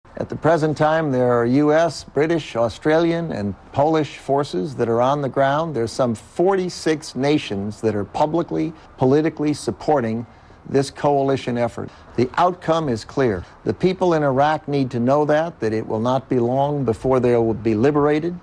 Mówi Donald Rumsfeld (143Kb)